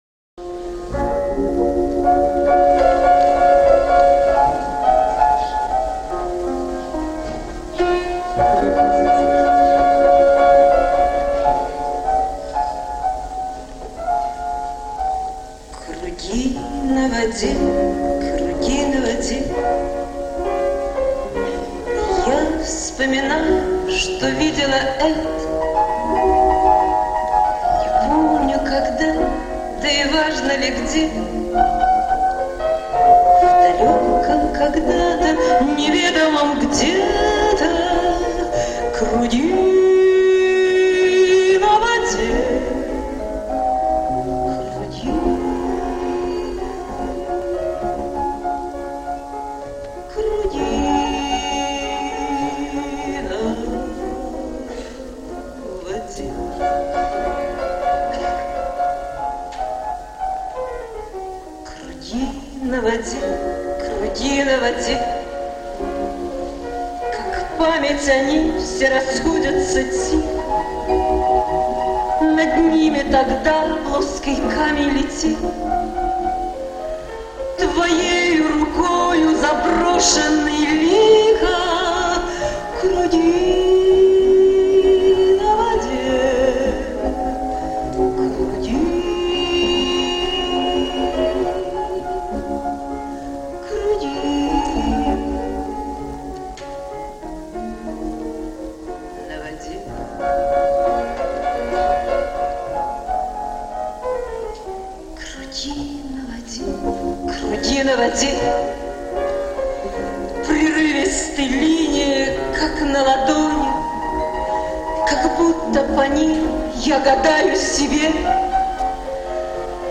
Запись с концерта
Концертный зал им. П. И. Чайковского. 1968 г. Музыка